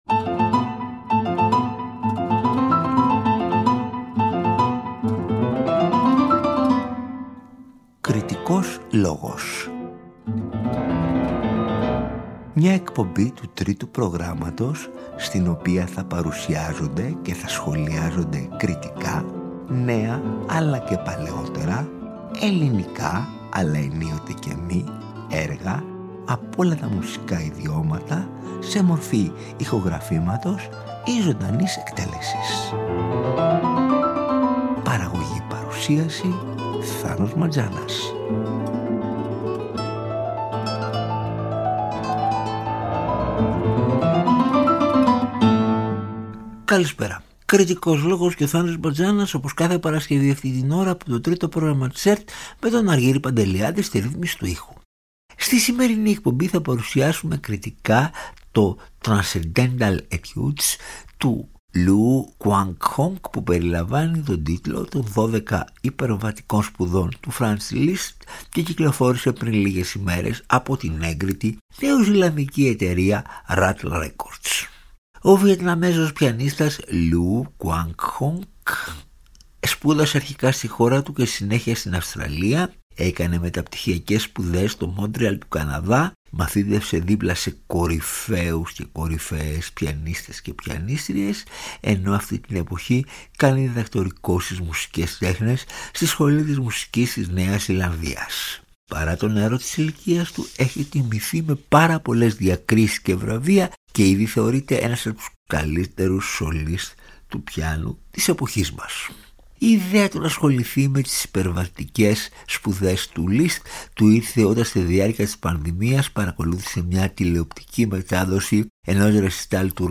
Ο Βιετναμέζος πιανίστας